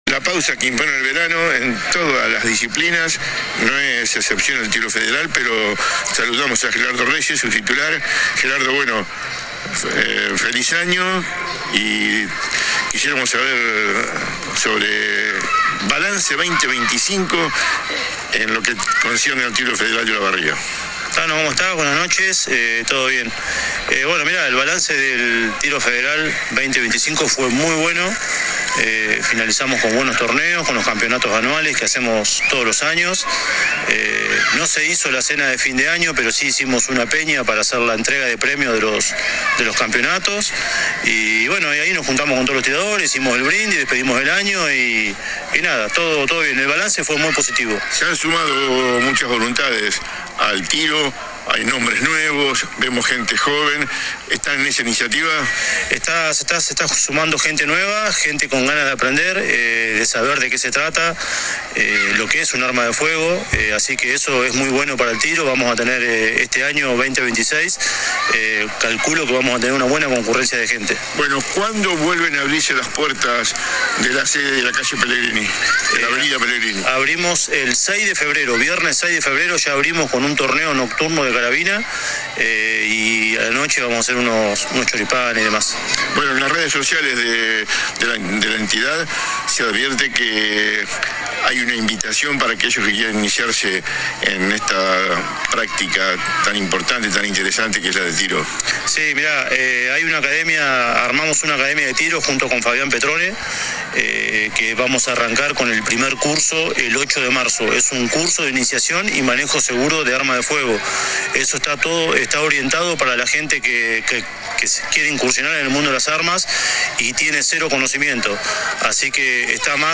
Analizó el año que pasó y adelantó importantes novedades para el año que se inicia. AUDIO DE LA ENTREVISTA.